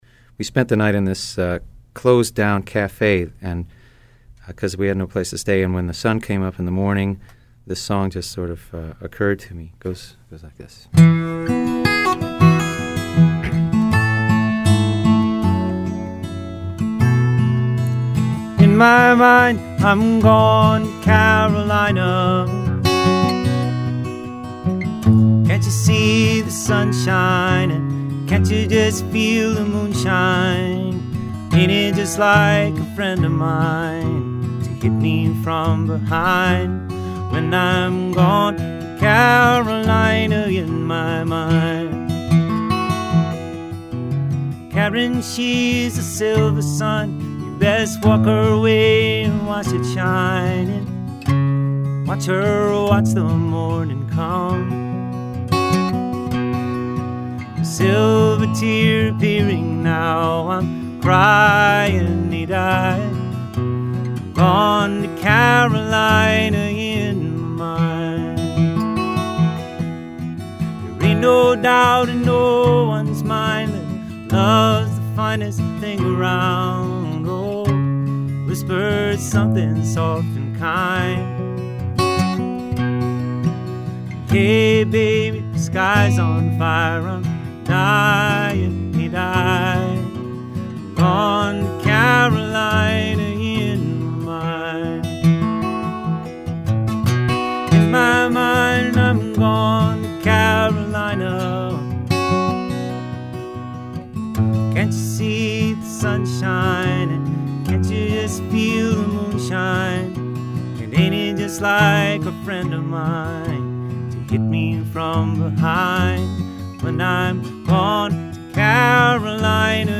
no overdubs.